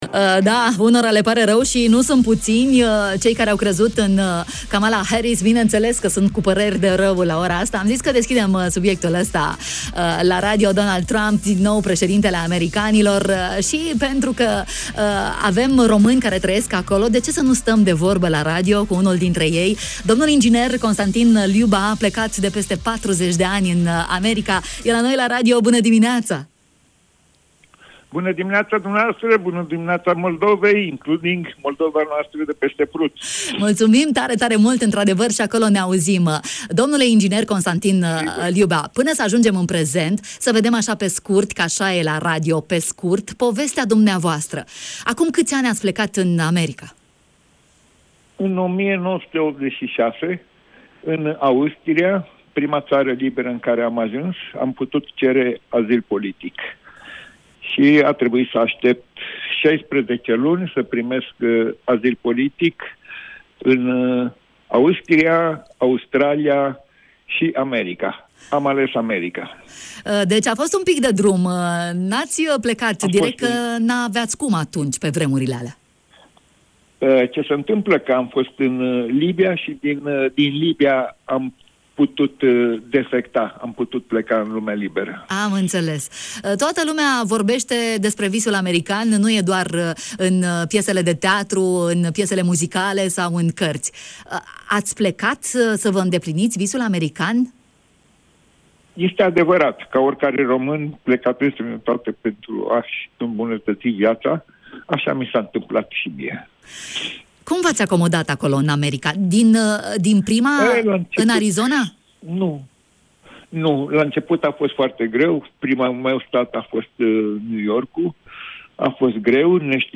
L-am intrebat în matinal cum e visul american și ce așteptări are de la noul presedinte: